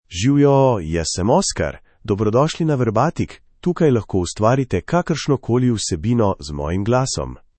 Oscar — Male Slovenian AI voice
Oscar is a male AI voice for Slovenian (Slovenia).
Voice sample
Listen to Oscar's male Slovenian voice.
Male
Oscar delivers clear pronunciation with authentic Slovenia Slovenian intonation, making your content sound professionally produced.